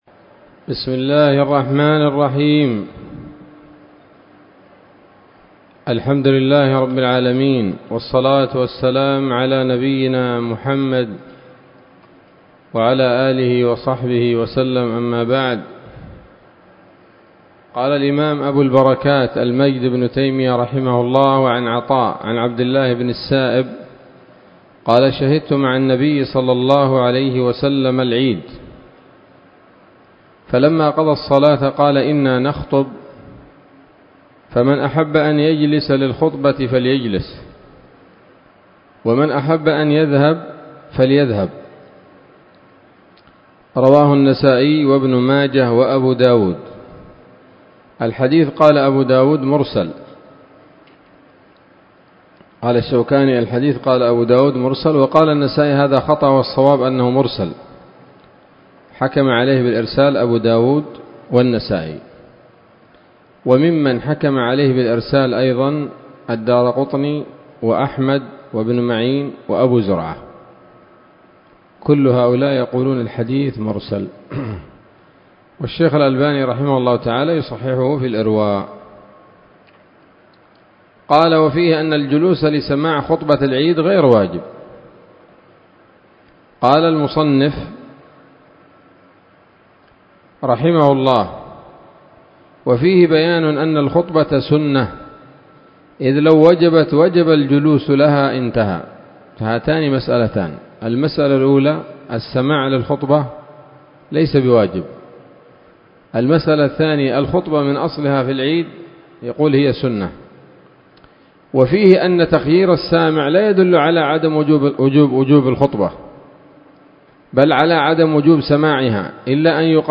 الدرس الخامس عشر من ‌‌‌‌كتاب العيدين من نيل الأوطار